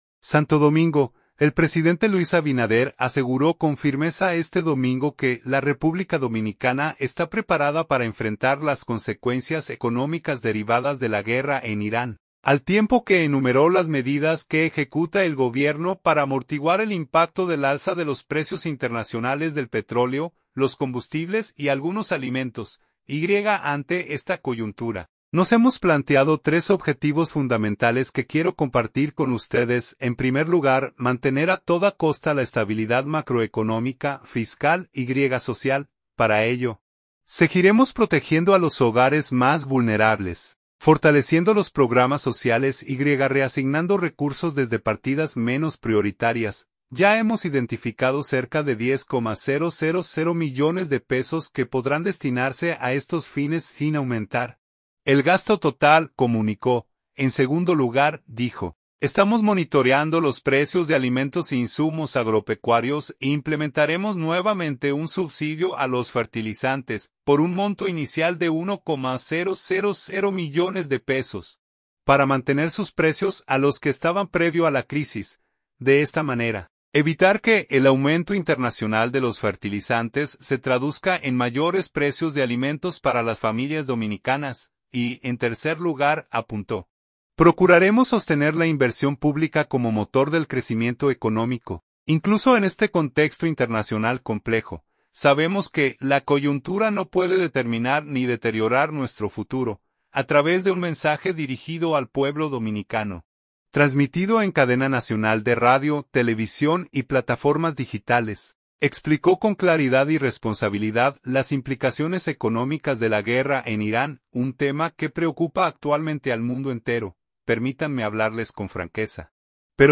A través de un mensaje dirigido al pueblo dominicano, transmitido en cadena nacional de radio, televisión y plataformas digitales, explicó con claridad y responsabilidad las implicaciones económicas de la guerra en Irán, un tema que preocupa actualmente al mundo entero.